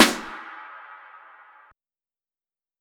TM88 ScarySnare.wav